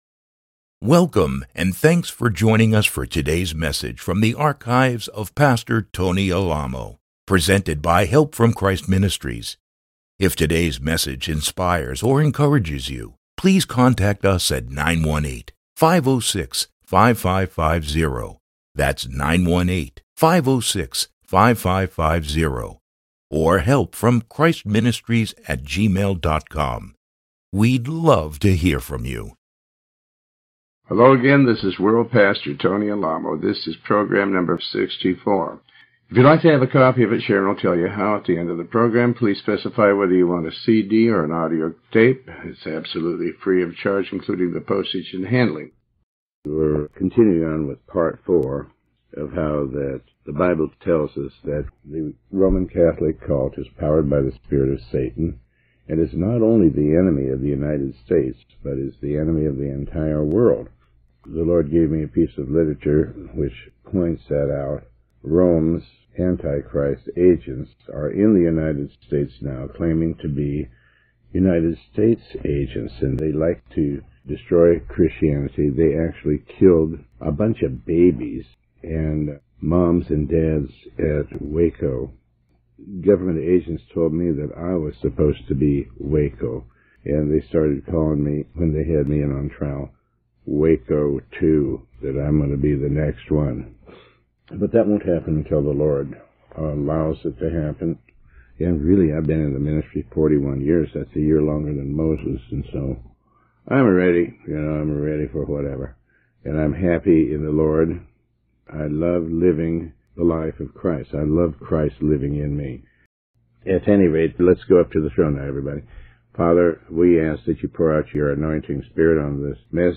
Sermon 64B